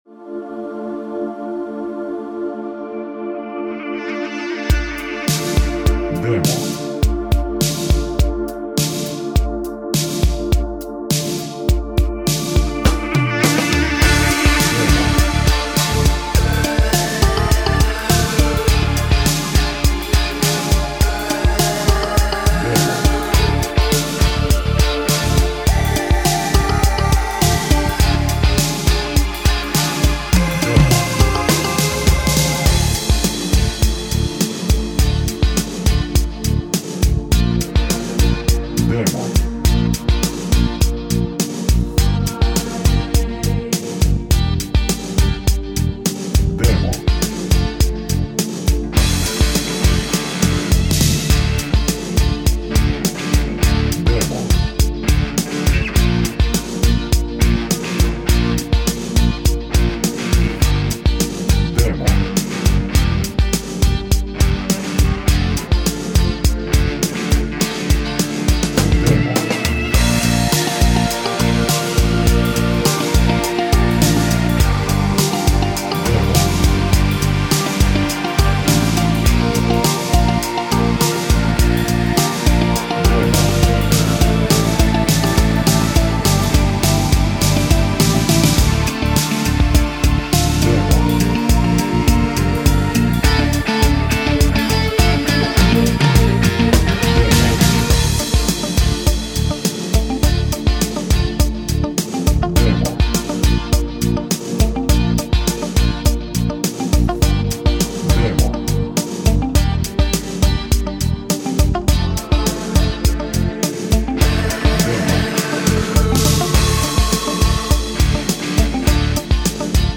минус без бэк-вокала